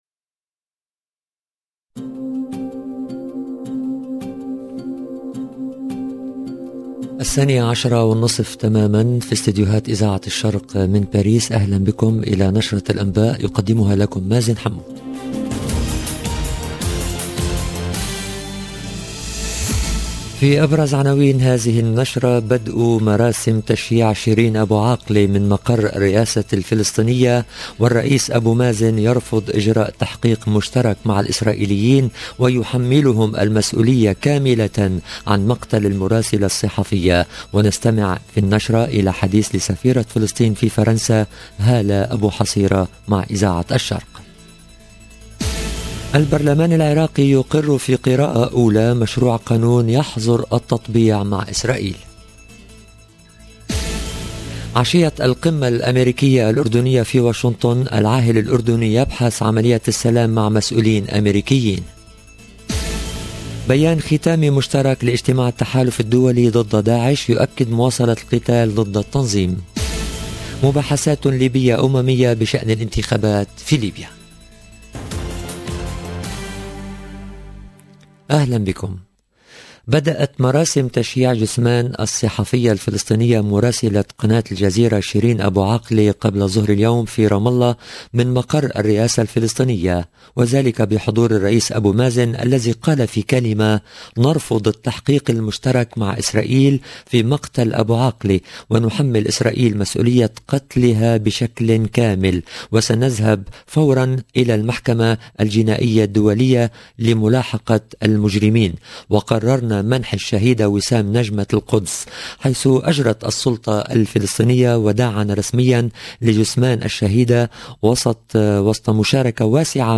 LE JOURNAL EN LANGUE ARABE DE LA MI-JOURNEE DU 12/05/22